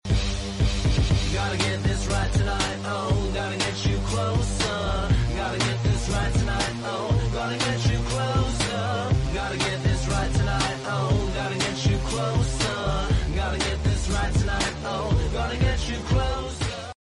(Yes, the quality was TOTALLY RUINED!!!!!)